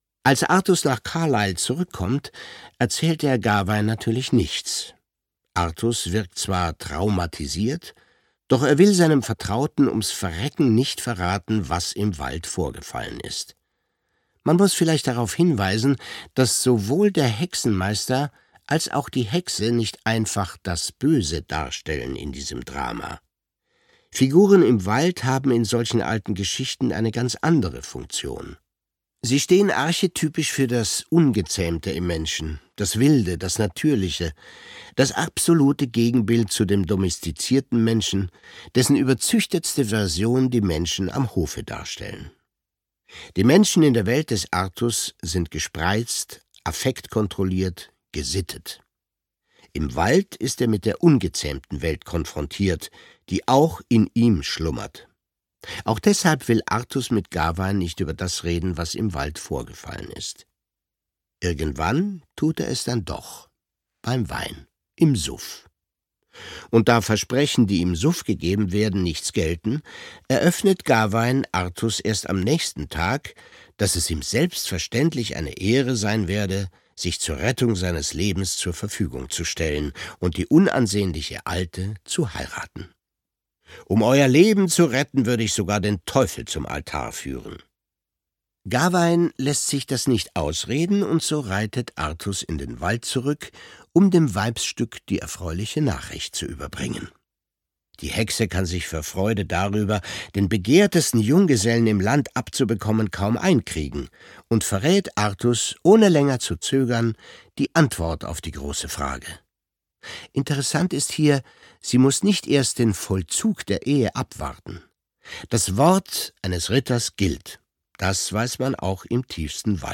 Die Kunst des lässigen Anstands - Alexander von Schönburg - Hörbuch